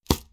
Close Combat Attack Sound 14.wav